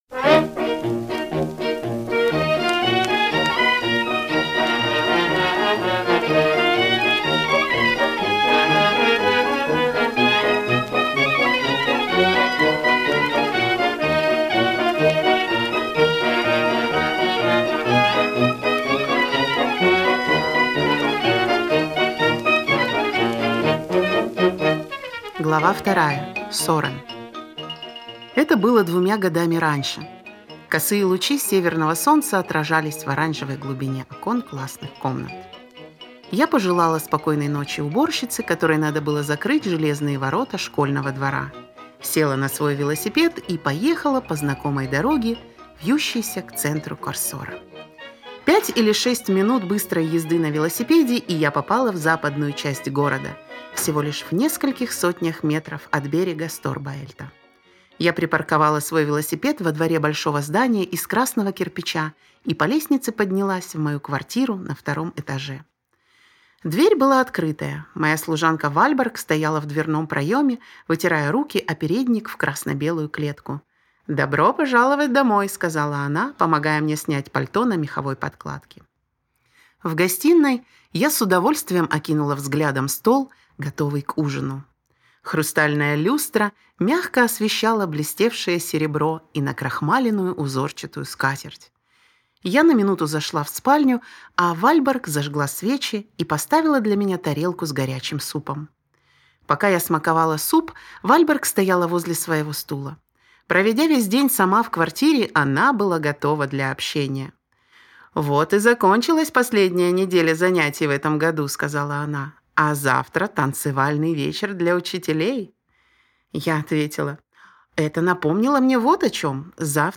В конце и в начале каждой главы использовались отрывки из антологии еврейской музыки, записи, которые по времени совпадают с хроникой событий описанных в книге.
Использовалась и современная еврейская музыка, песни, которые слышал каждый израильтянин.